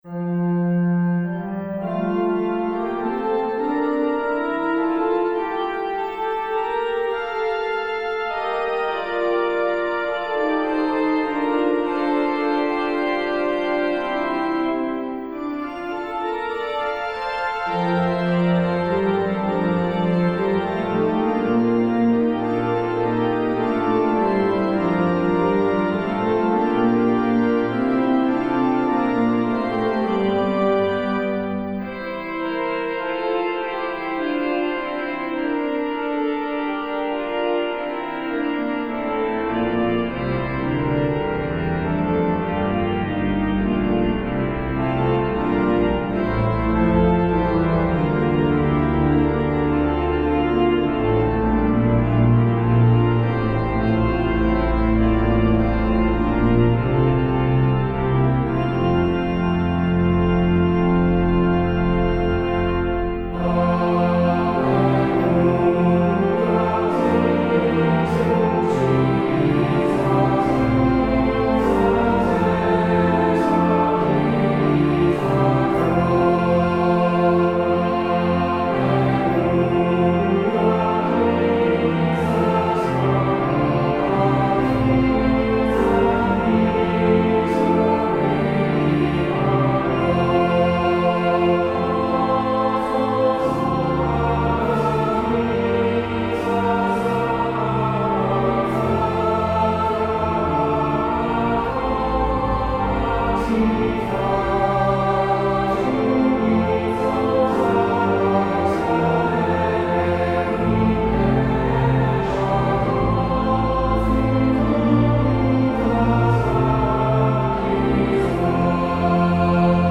Free descant to the hymn tune HYFRYDOL - 'Alleluia, sing to Jesus'